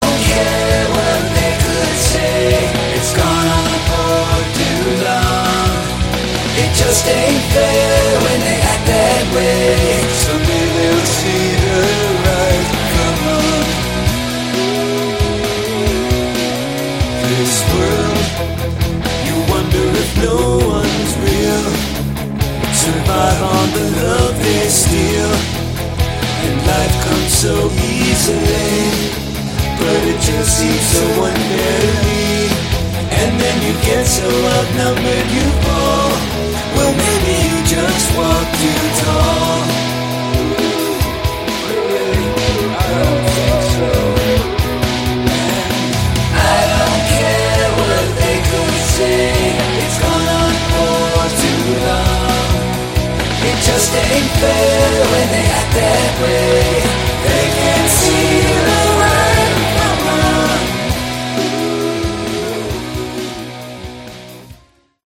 Category: AOR / Melodic Hard Rock